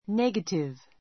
negative A2 néɡətiv ネ ガティ ヴ 形容詞 ❶ 否定の; 反対の 反対語 positive （肯定 こうてい の） a negative sentence a negative sentence 文法 否定文 Don't look at things in a negative way.